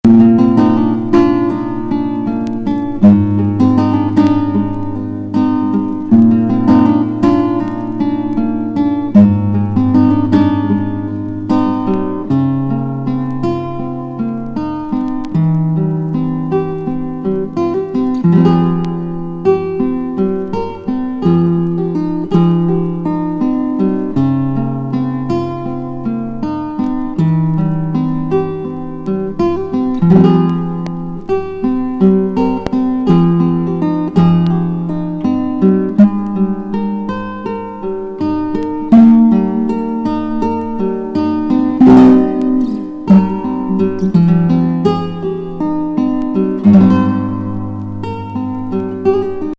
フルート、タブラ、シェーカー、声等